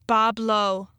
bob-low in the Michigan tradition of butchering names of French origin, has been lucky.